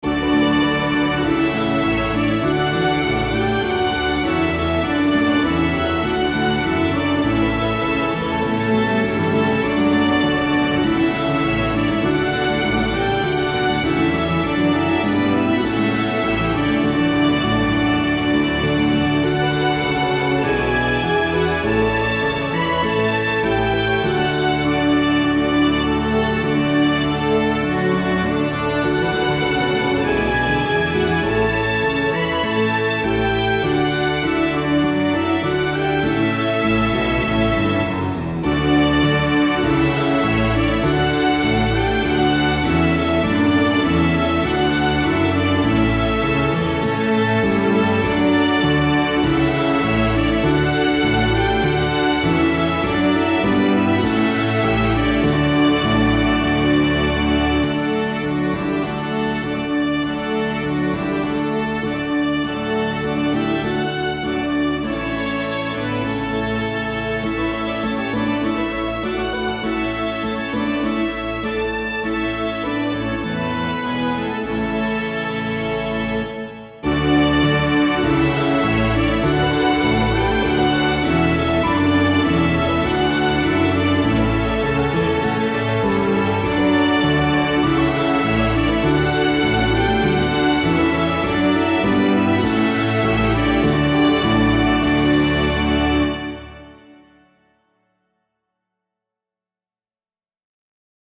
After pondering everything for a while, I decided to ornament the Harpsichord (Miroslav Philharmonik) and to add a second Solo Trumpet, as well as a Pipe Organ and a Cathedral Organ, although this probably moves it in a different direction from Baroque . . .